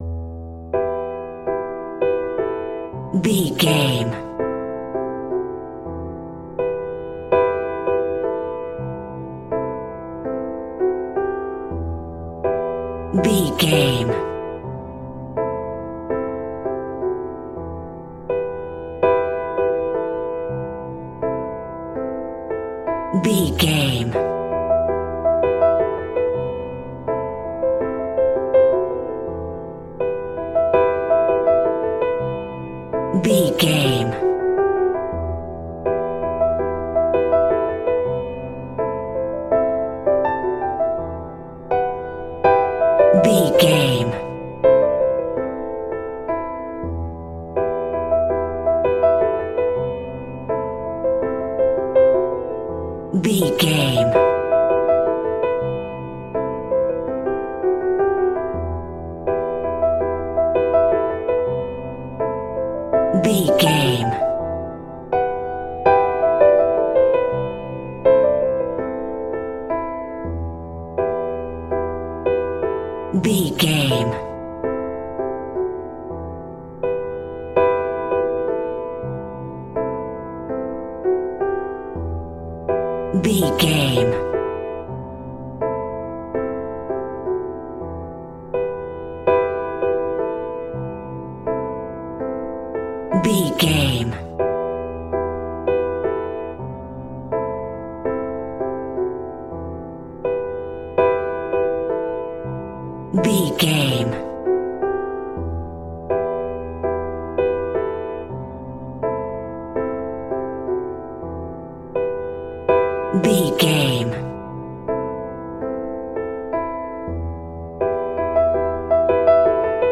Light and chilled ambient piano melodies in a major key.
Regal and romantic, a classy piece of classical music.
regal
romantic
soft